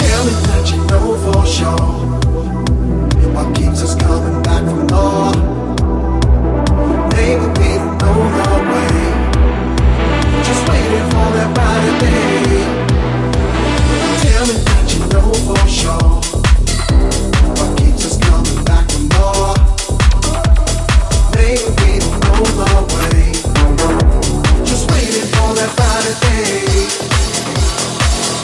Genere: deep,dance,news